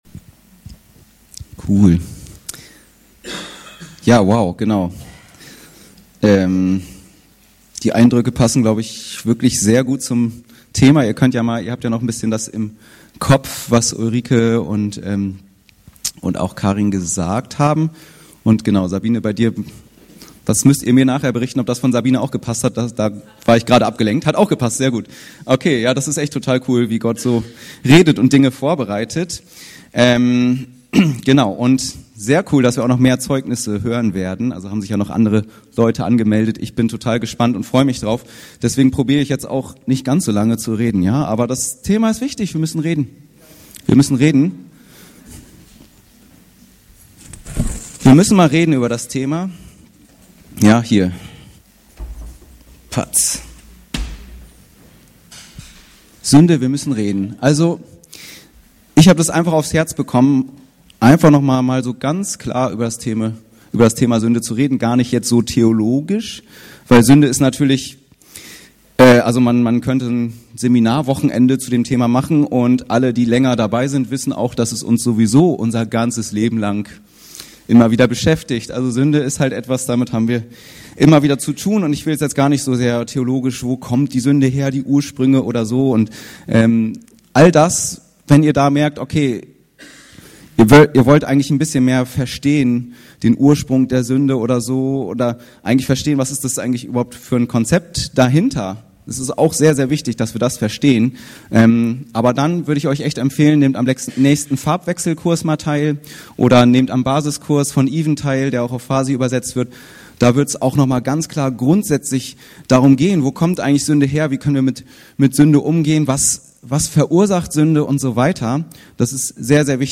Sünde - Wir müssen reden ~ Anskar-Kirche Hamburg- Predigten Podcast